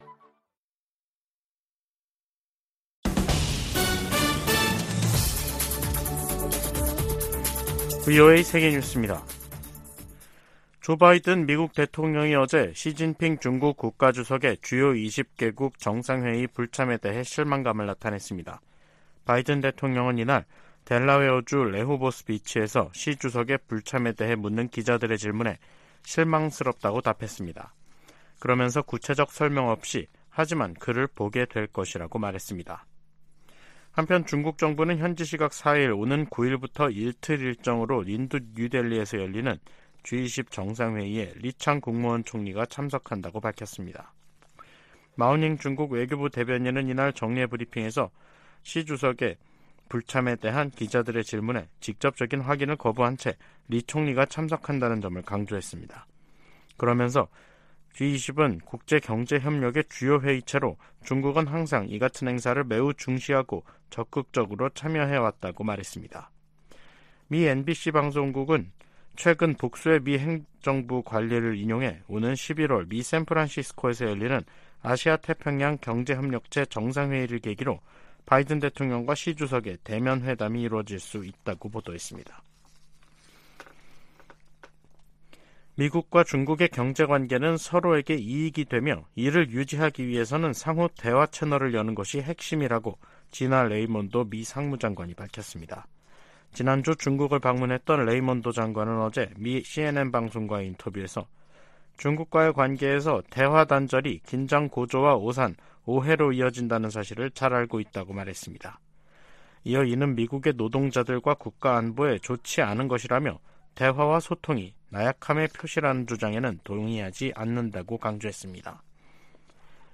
VOA 한국어 간판 뉴스 프로그램 '뉴스 투데이', 2023년 9월 4일 2부 방송입니다. 북한이 전략순항미사일을 발사하며 핵 공격 능력을 과시하려는 도발을 이어갔습니다. 러시아가 북한에 북중러 연합훈련을 공식 제의했다고 한국 국가정보원이 밝혔습니다. 백악관은 바이든 행정부가 인도태평양 지역을 중시하고 있다고 거듭 강조했습니다.